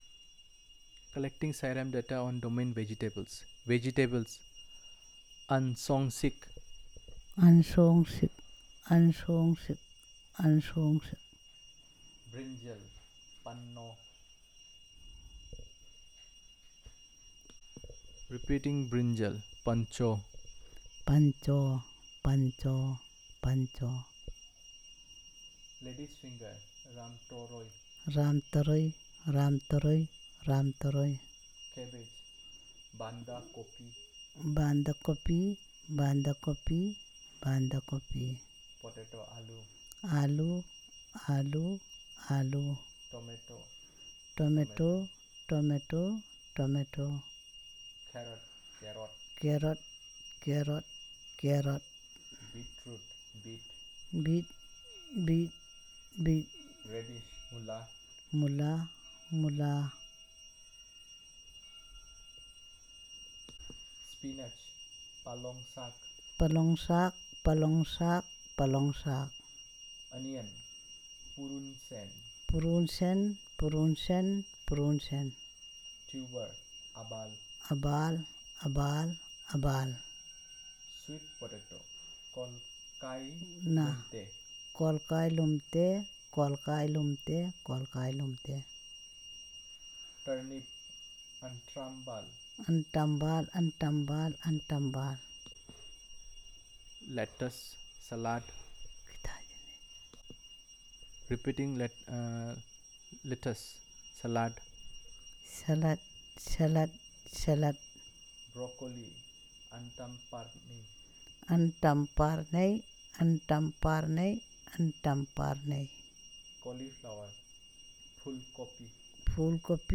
Elicitation of words about vegetables and related